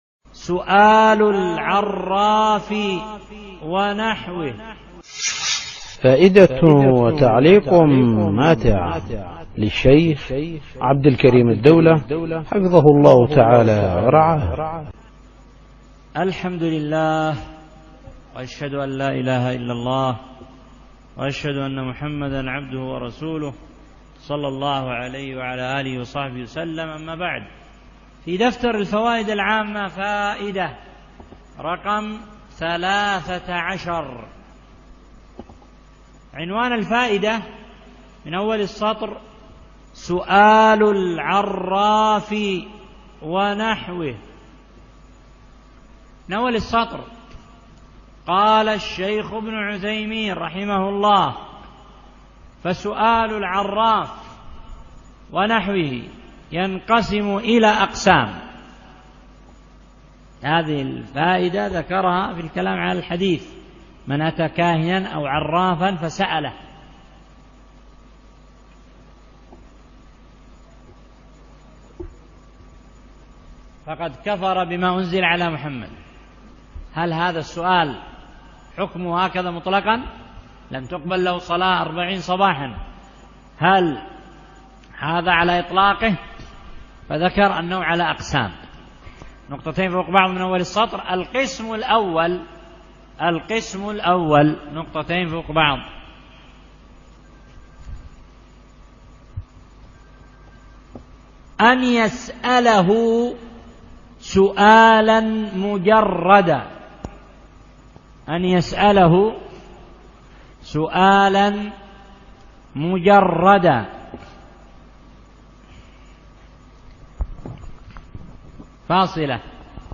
في مسجد الرحمن الجراحي الحديدة اليمن